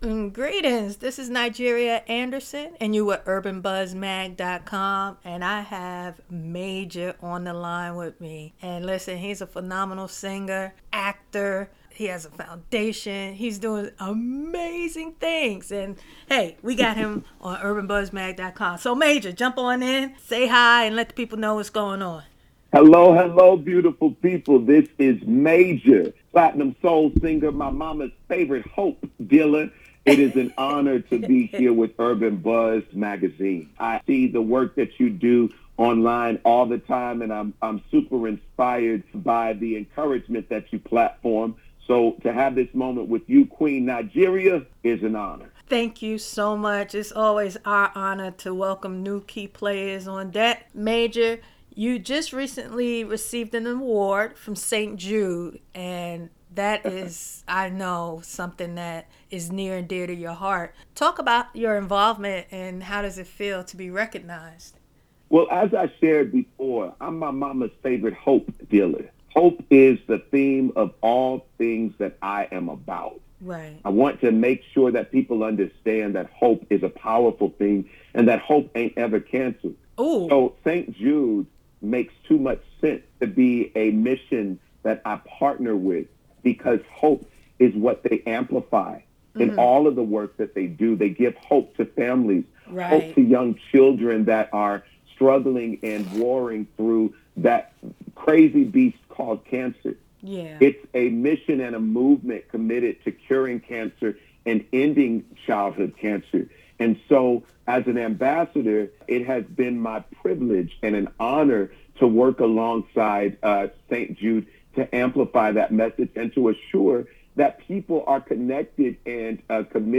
In our interview he professed he actually prayed for you, the listener.